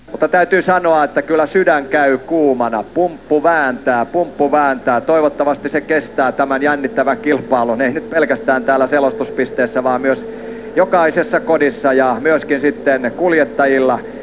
pumppu.wav